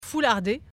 prononciation Foulardé ↘ explication Il y a le mot “foulardé”.